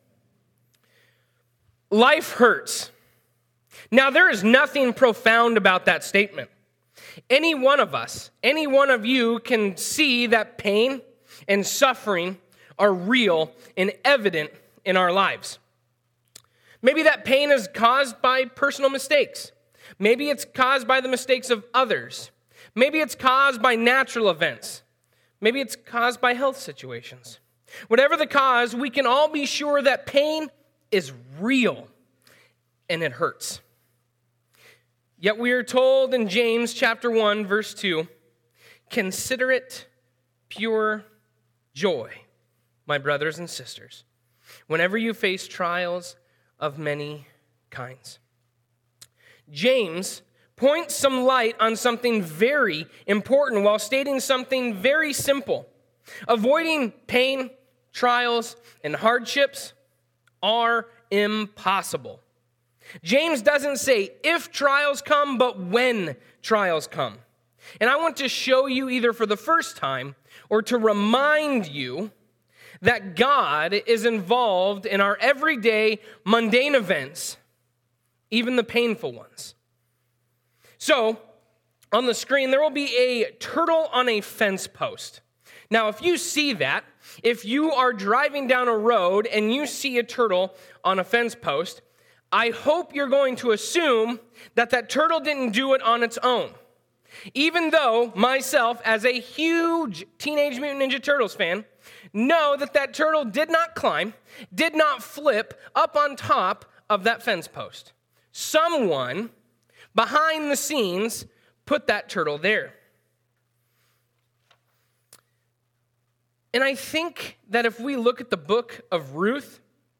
Misc Sermons